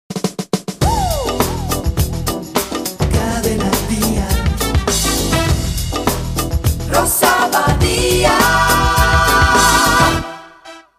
"Jingle"
FM